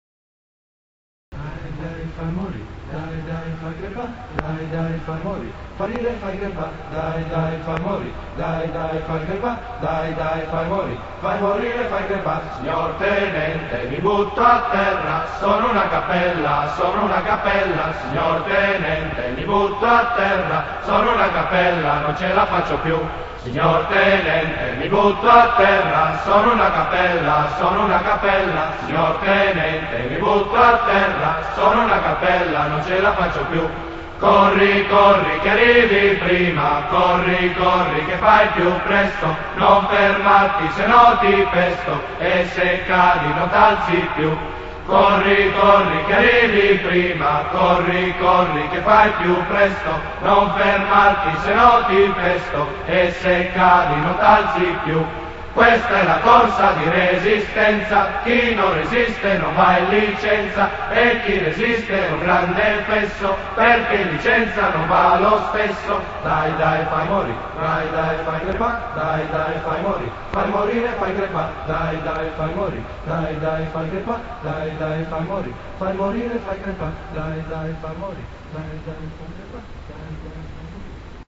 passo di corsa cantata.mp3